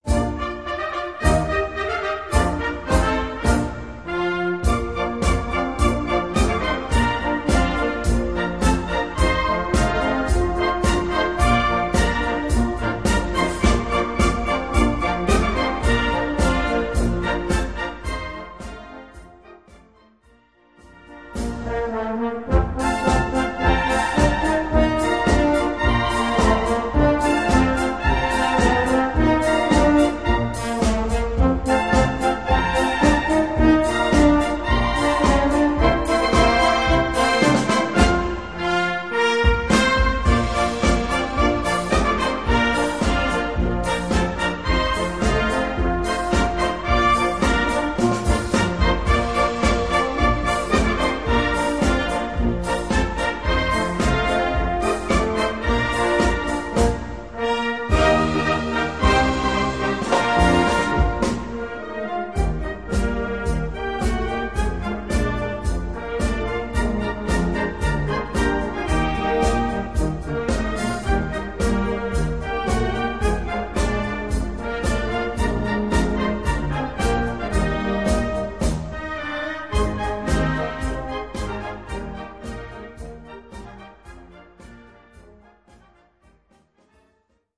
Gattung: Beat-Polka
Besetzung: Blasorchester